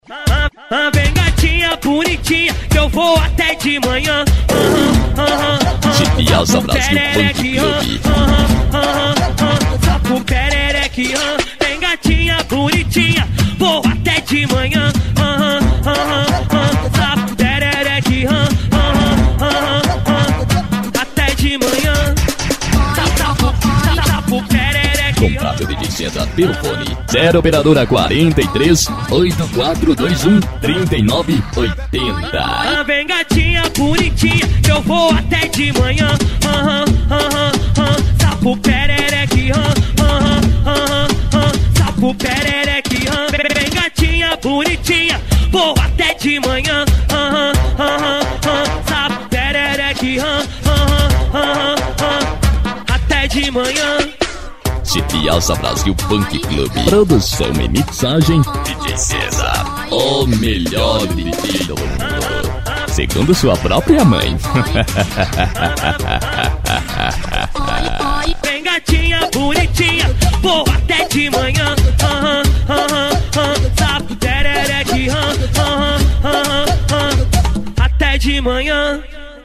Funk
Funk Nejo